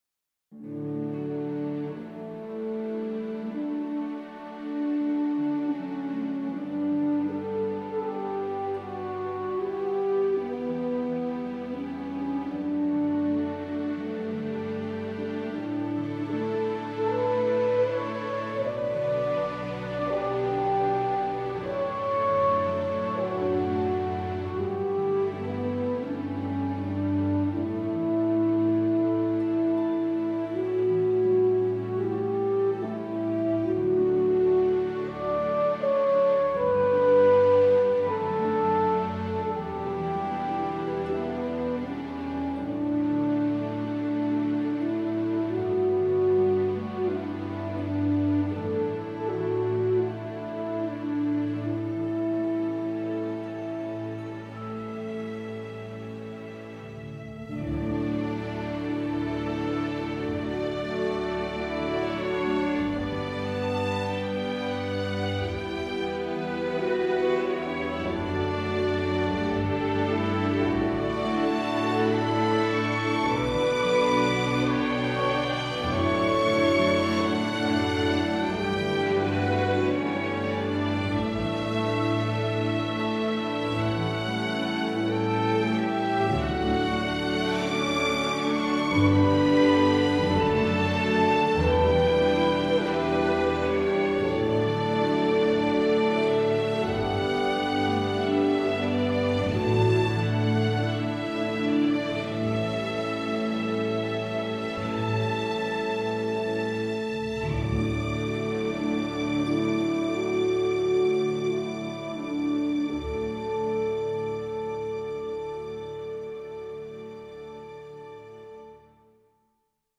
管弦乐
Cinesamples CineBrass Descant Horn 是一款高音圆号的虚拟乐器，它能够提供丰满而清晰的声音，非常适合独奏和高音乐句。
Cinesamples CineBrass Descant Horn 的音色非常逼真和动态，能够表现出不同力度和演奏技巧的细微变化。它包含了多种演奏模式，如单音连奏、多音连奏、短音、双舌、三舌、延长短音、强烈短音和持续音等，可以满足不同风格和场景的需求。